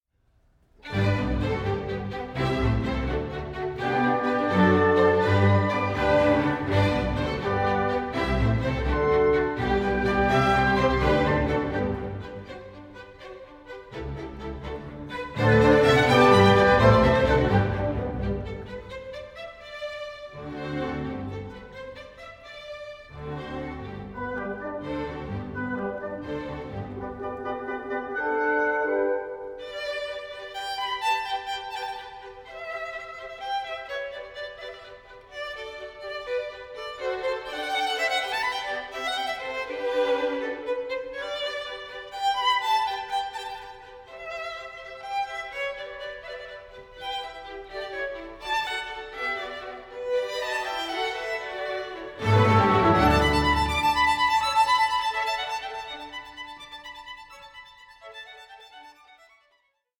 Adagio 8:24